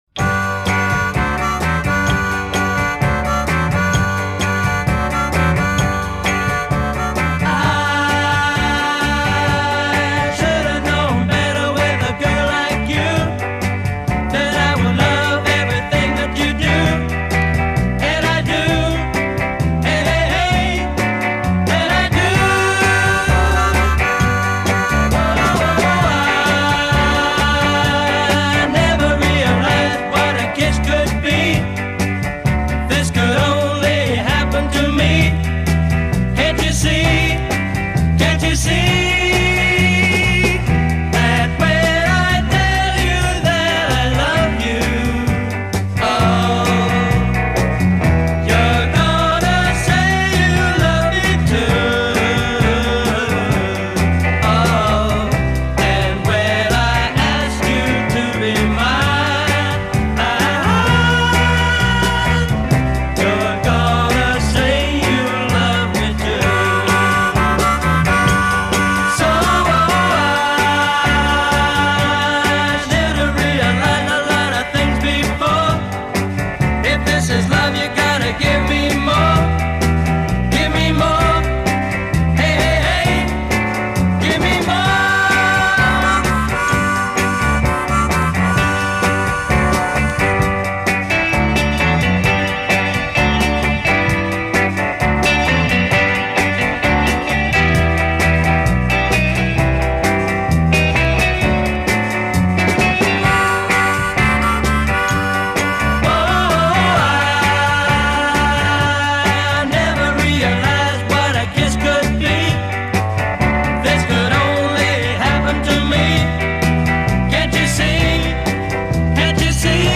Очень близко к оригиналу.